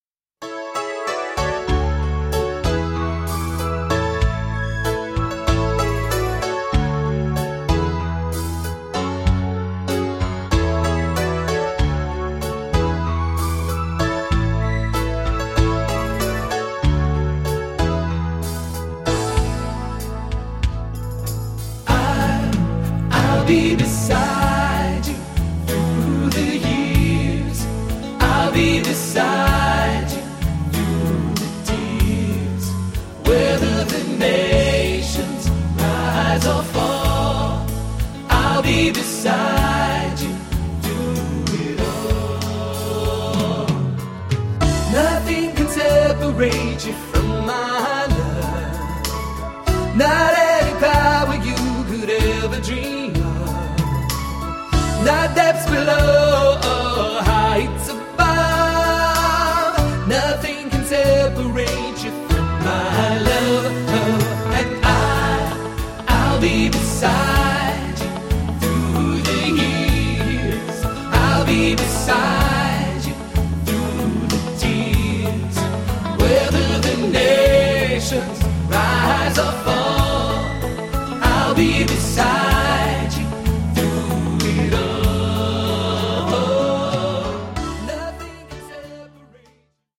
poppige Jugendchor